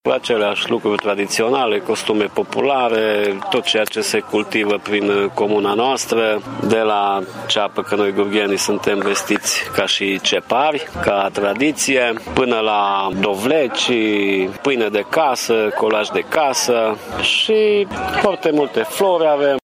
Preşedintele Asociaţiei Comunităţii Văii Gurghiului, primarul comunei Gurghiu, Laurenţiu Boar: